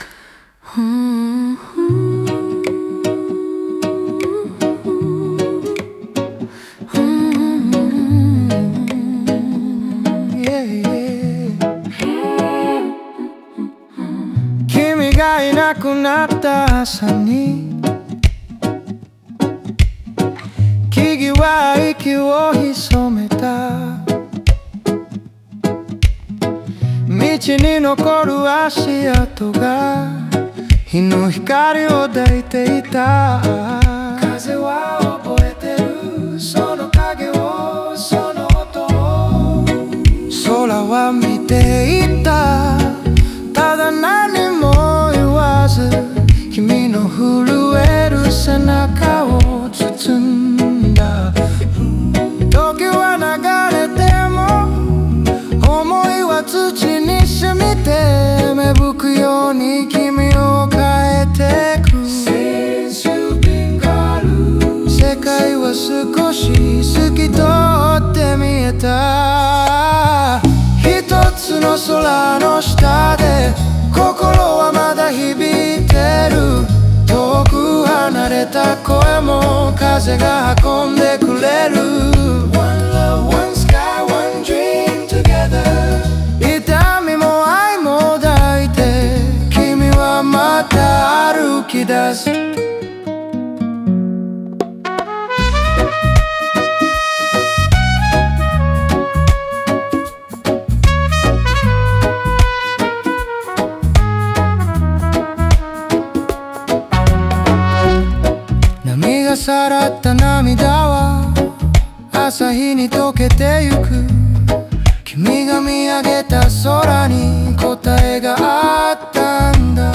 オリジナル曲♪
レゲエのリズムとアコースティックの温もりが重なり、バックコーラスは自然界の声として寄り添う。